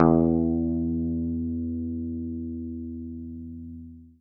guitar-electric
E2.wav